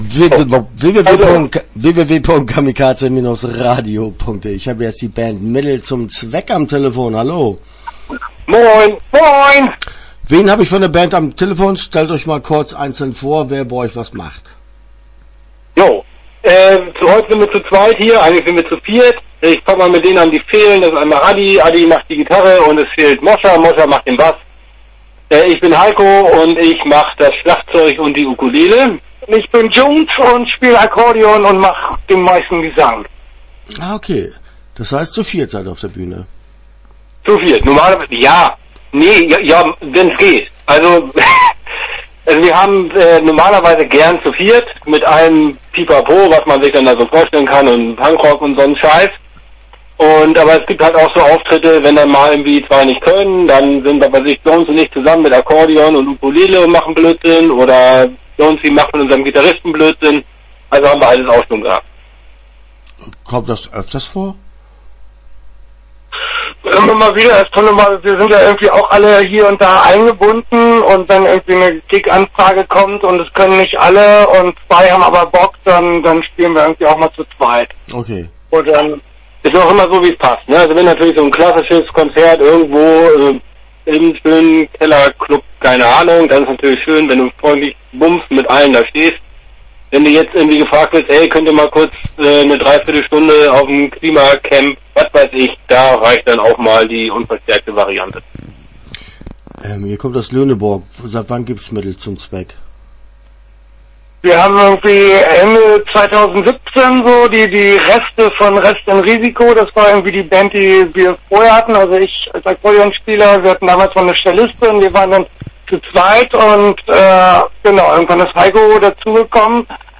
Start » Interviews » Mittel zum Zweck